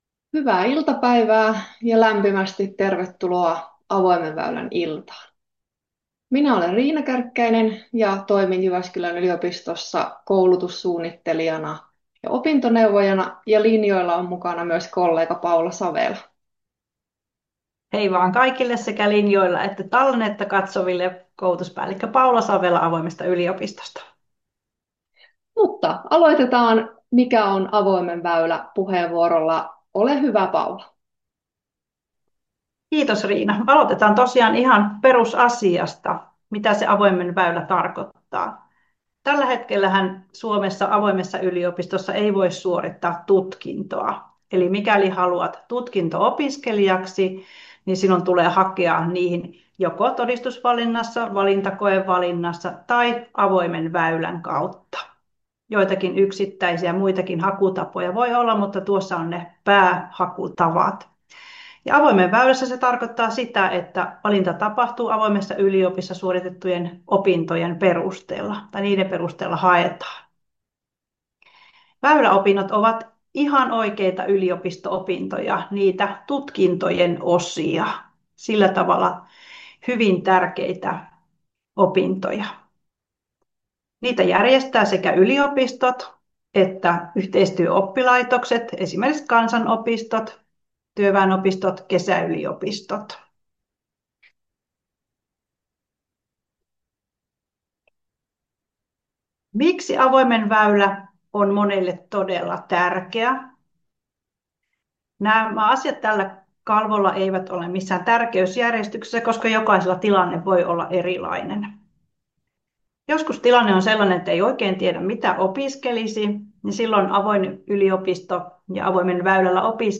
Aloituspuheenvuoro